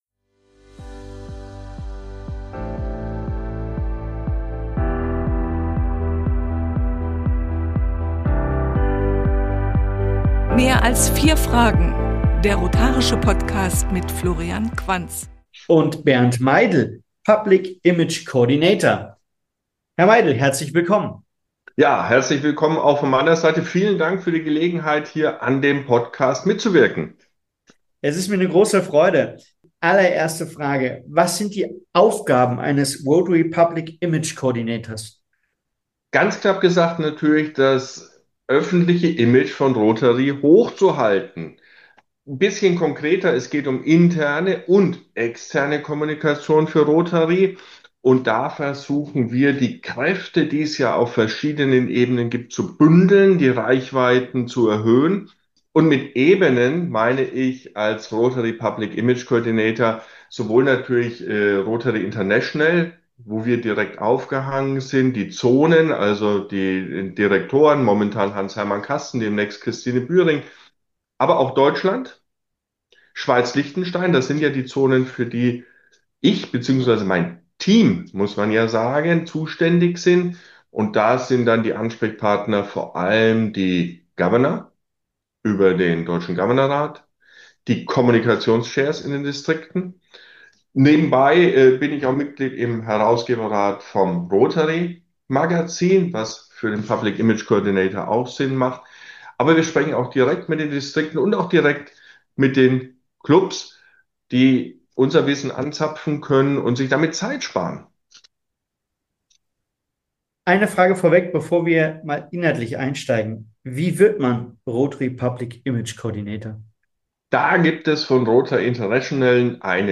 Diesmal im Gespräch